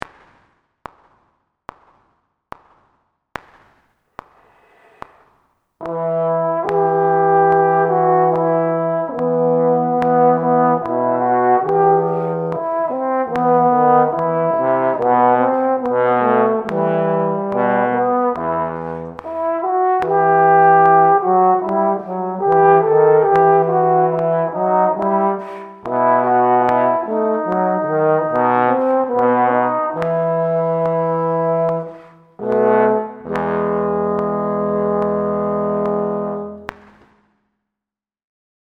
Here’s the audio of the (individual) harmony parts.
Min-04-e.mp3